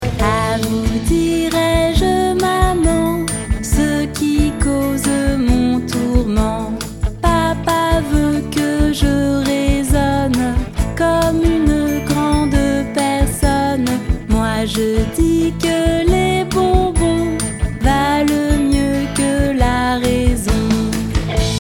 In this traditional French children’s song
Traditional music